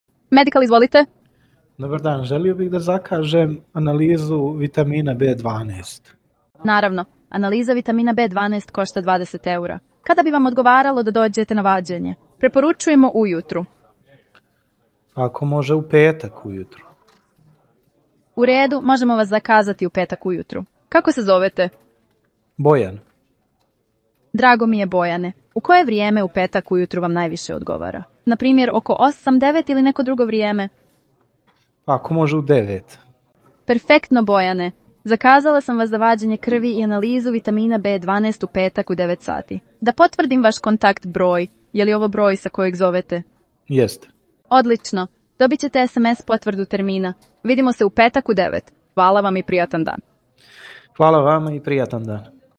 This intelligent system engages in real-time conversations with users, delivering accurate information through a natural, human-like voice.
Listen to AI voice agent examples